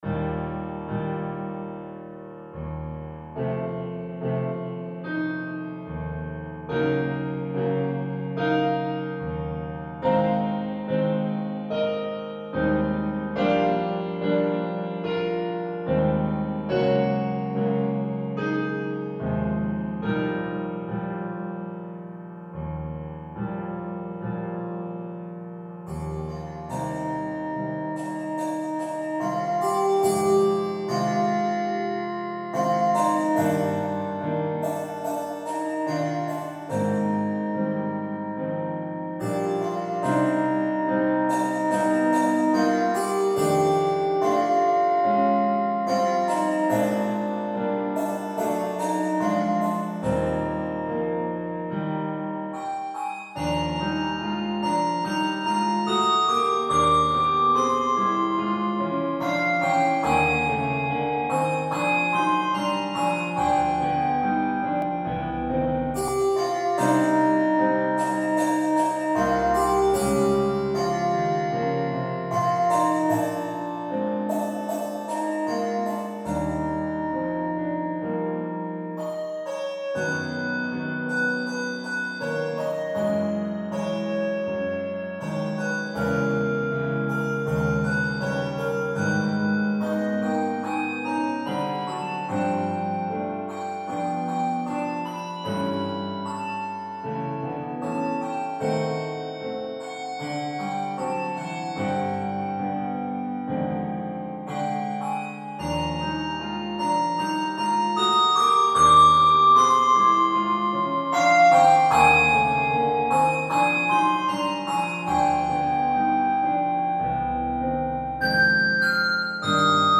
handbell solo arrangement